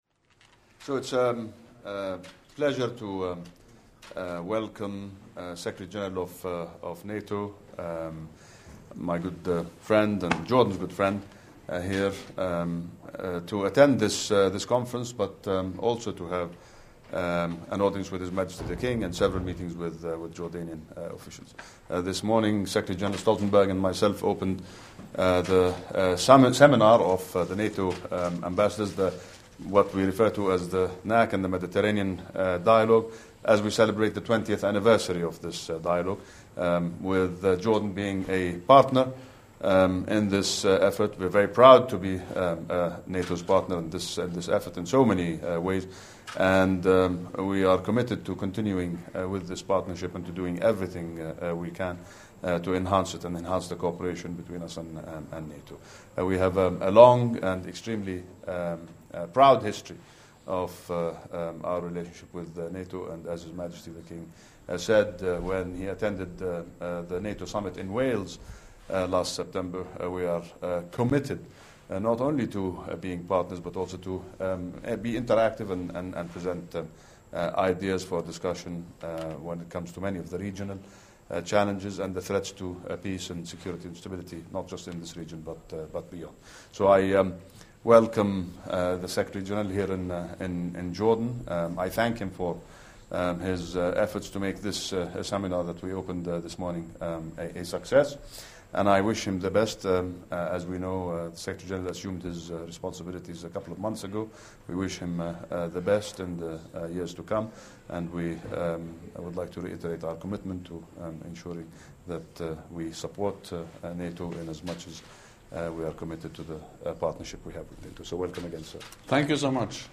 Remarks by NATO Secretary General Jens Stoltenberg at the joint press point with Nasser Judeh, Minister of Foreign Affairs of the Hashemite Kingdom of Jordan
Joint press point with NATO Secretary General Jens Stoltenberg and Minister of Foreign Affairs Nasser Judeh of the Hashemite Kingdom of Jordan